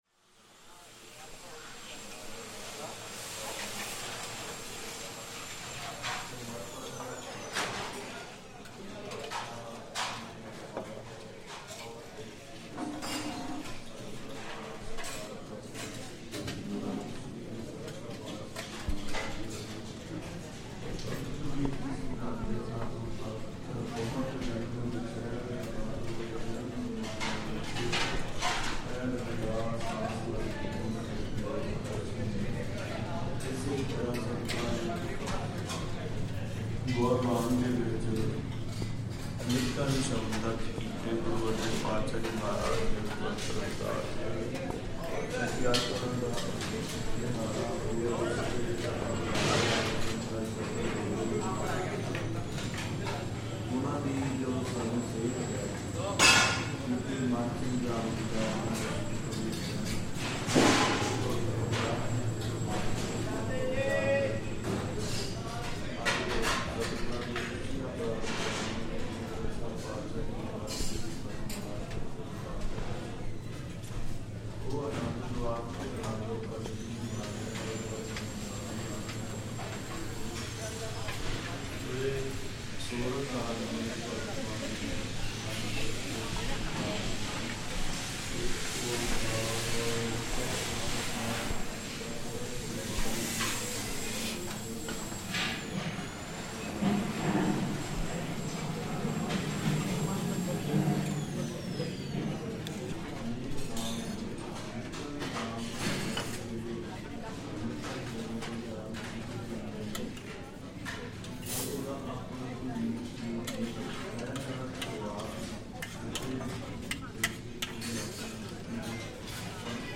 An incredibly welcoming space, this Gurdwara in Southall offers a community kitchen that is free and open to all to come in and enjoy some food - this soundscape is from inside the community kitchen with clattering trays and cutlery, food being served, the sound of washing and drying of hands, and the prayers from the temple being broadcast into the space via TVs and a sound system too.
Part of the Migration Sounds project, the world’s first collection of the sounds of human migration.